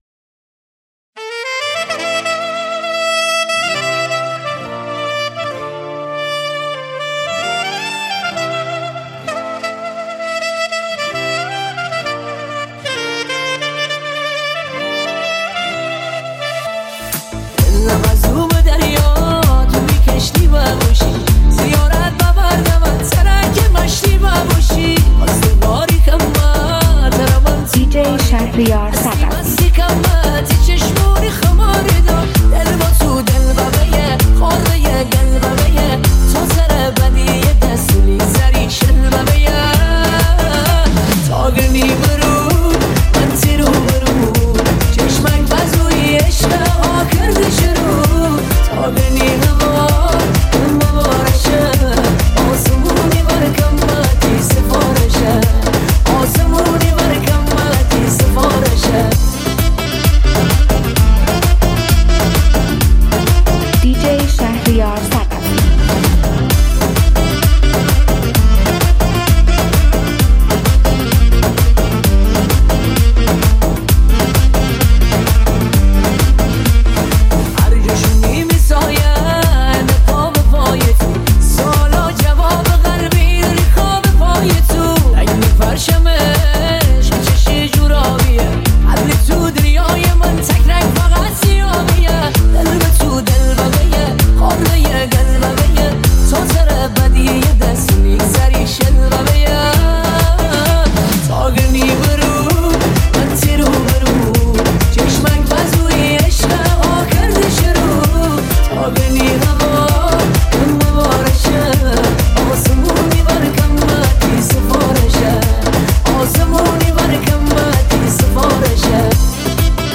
ریمیکس شمالی